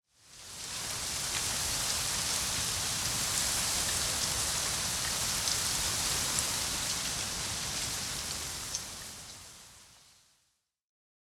windtree_6.ogg